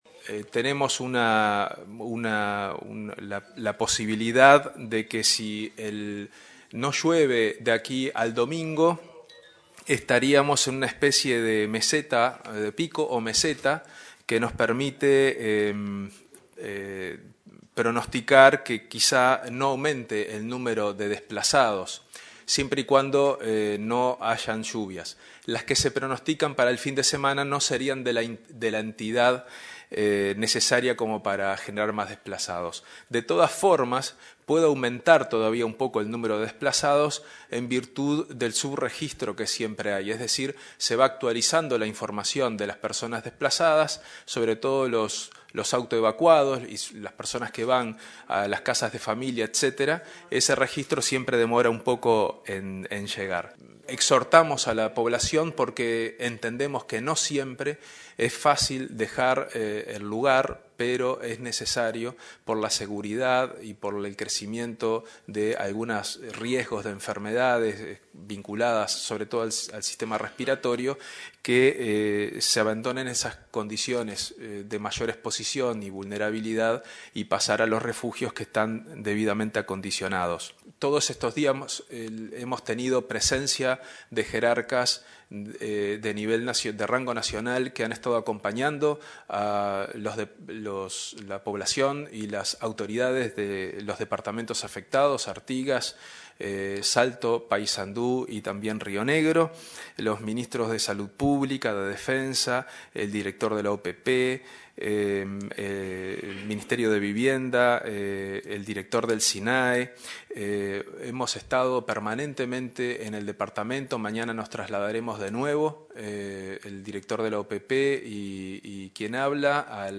En este momento hay 6.100 desplazados que pueden llegar a 7.000 porque aún hay personas que no se han registrado”, señaló el prosecretario Juan Andrés Roballo, tras la reunión de la Junta Nacional de Emergencias y Reducción del Riesgo. Dijo que el presidente Tabaré Vázquez dispuso un monitoreo permanentemente de los afectados en el noroeste del país.